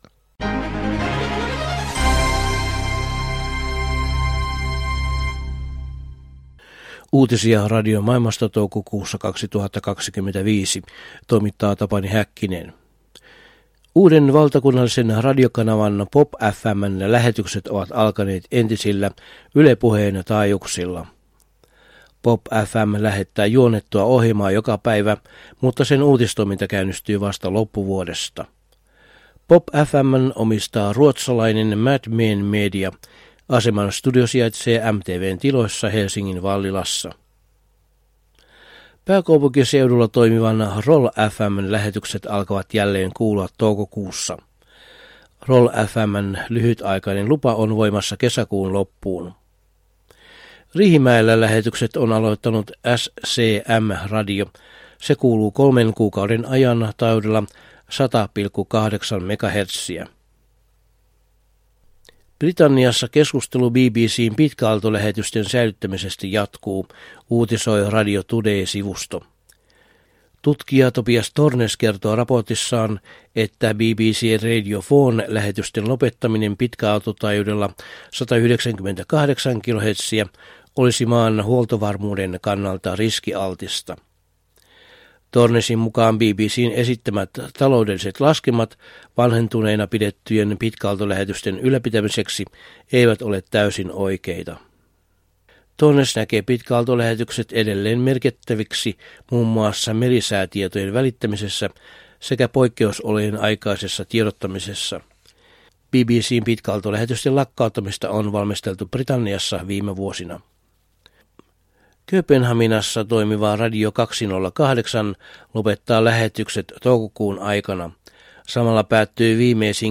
Toukokuun 2025 uutislähetyksessä aiheina ovat muun muassa uuden valtakunnallisen radiokanavan POPfm:n aloitus, Britanniassa virinnyt keskustelu BBC:n pitkäaaltolähetysten lopettamisesta ja muutosesitys radion toimilupien myöntämisperiaatteisiin Ruotsissa.